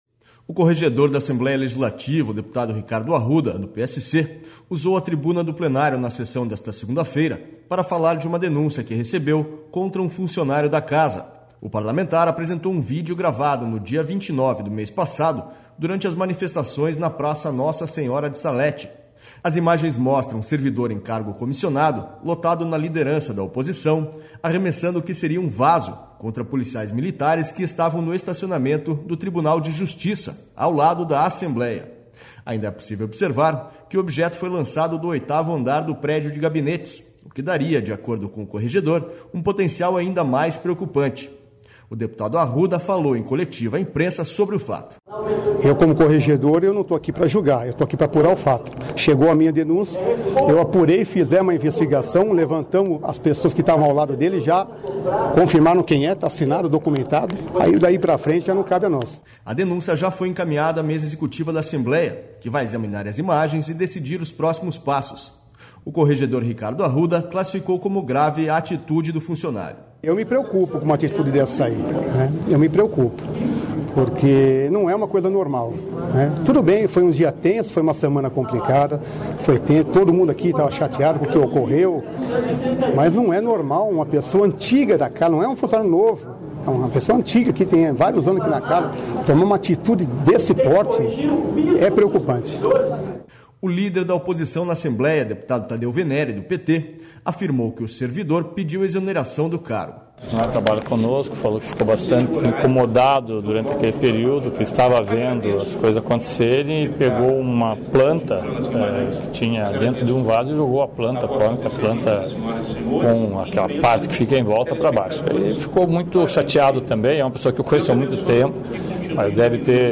O corregedor da Assembleia Legislativa, o deputado Ricardo Arruda, do PSC, usou a tribuna do plenário na sessão desta segunda-feira para falar de uma denúncia que recebeu contra um funcionário da Casa.//O parlamentar apresentou um vídeo gravado no dia 29 do mês passado, durante as manifestações na P...